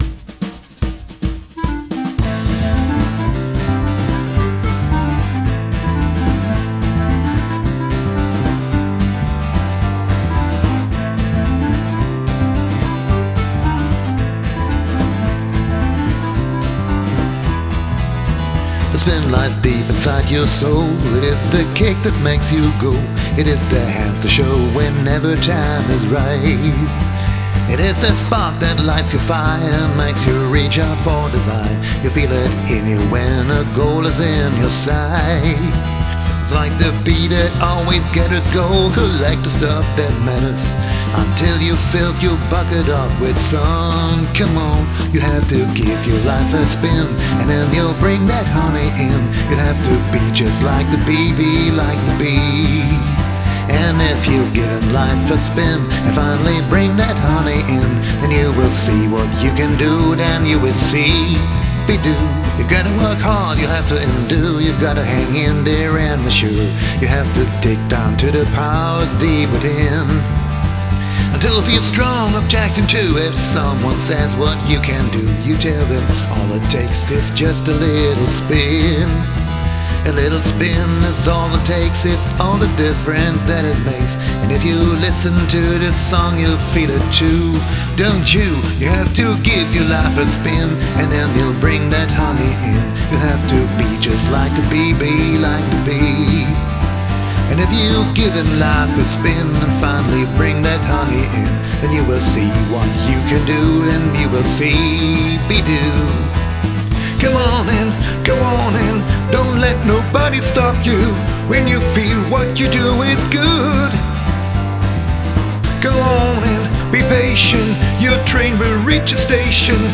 it works great as a Swing track.